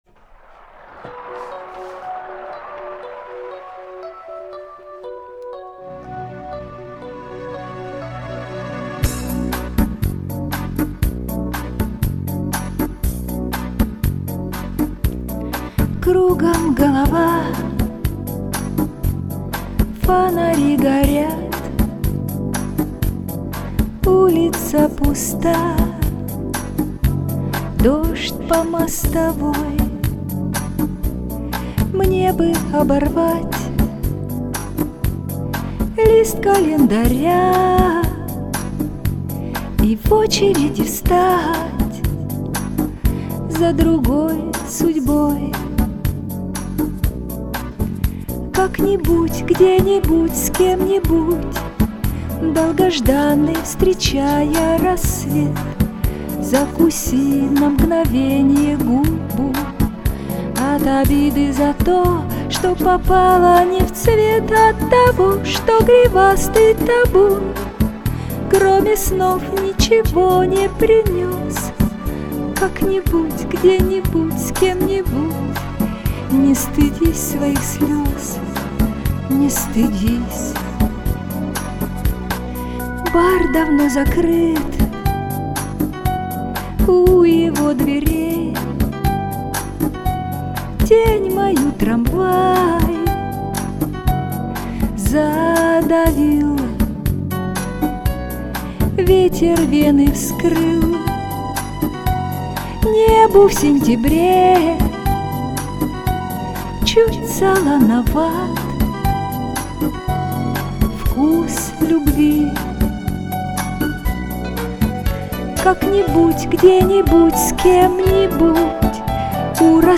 А в поединке использована минусовка из интернета.